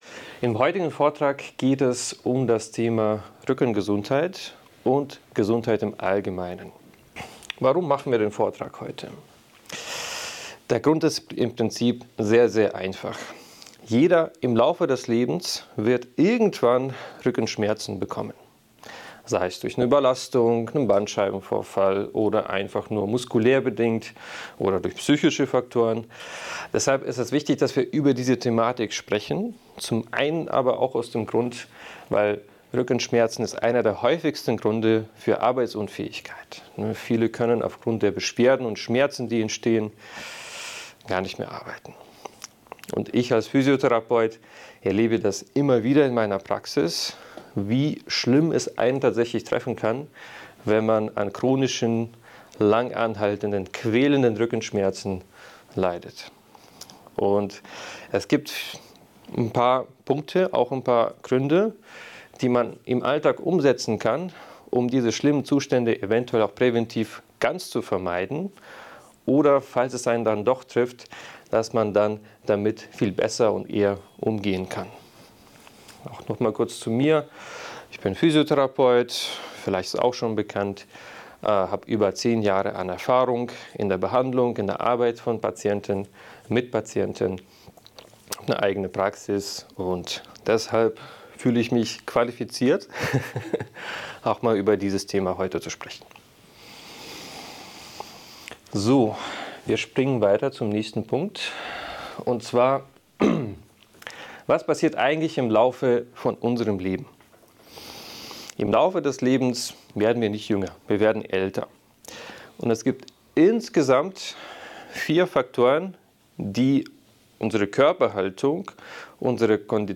In diesem Vortrag wird die Bedeutung der Rückengesundheit und die häufigsten Ursachen von Rückenschmerzen behandelt.